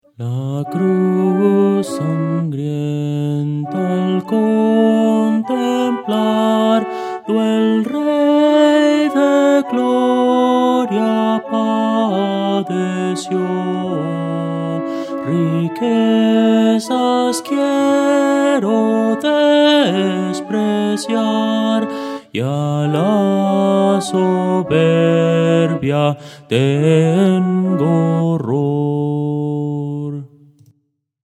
Voces para coro
Soprano – Descargar